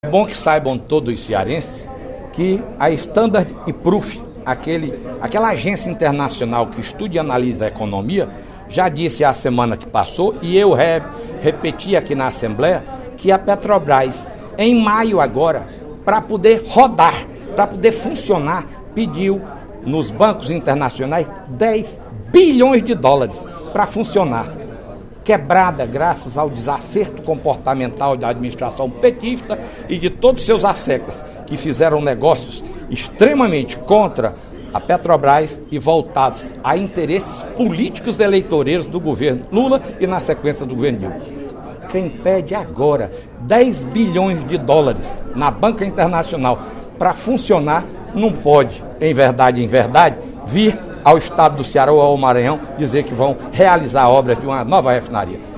O deputado Fernando Hugo (PSDB) criticou a Petrobras, durante a sessão plenária da Assembleia Legislativa desta terça-feira (11/06), pelo anúncio de mudanças no projeto da Refinaria Premium II, a ser instalada pela estatal no Complexo Industrial e Portuário do Pecém.